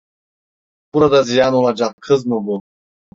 Pronounced as (IPA) /ziˈjan/